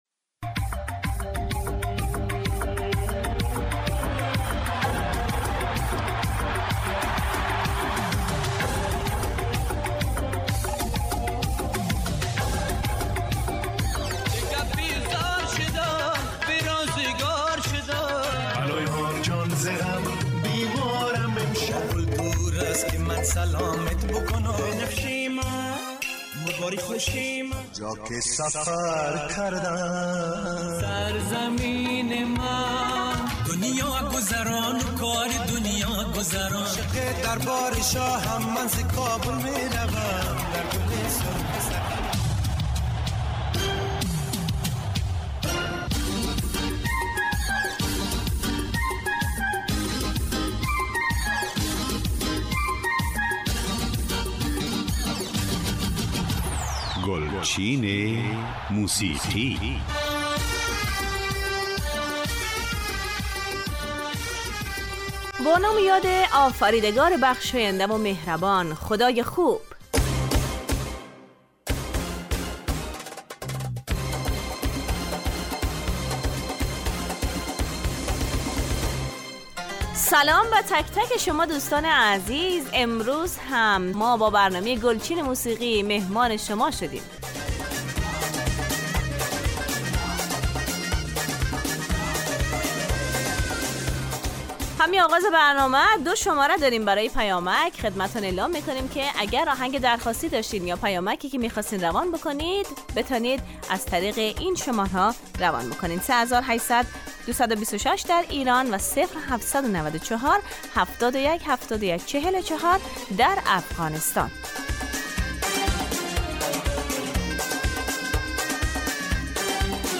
برنامه ای برای پخش ترانه های درخواستی شما عزیزان